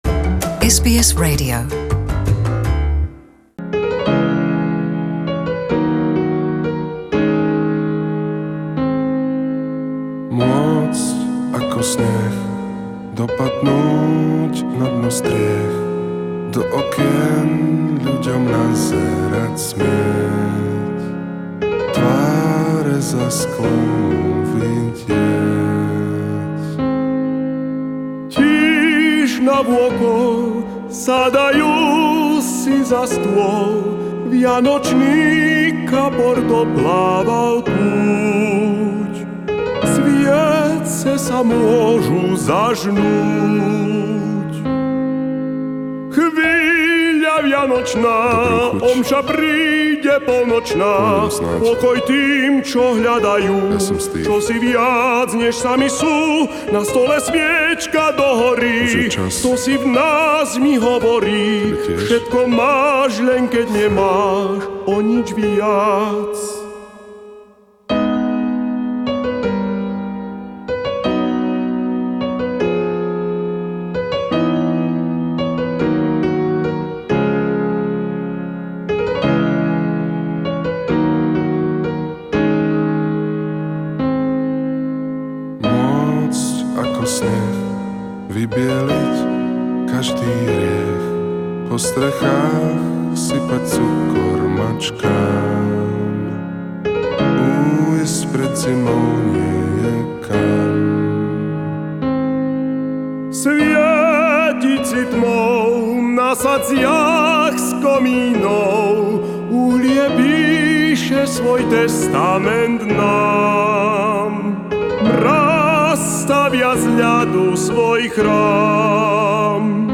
Mimoriadne slovenské vianočné vysielanie poslednú adventnú nedeľu 2018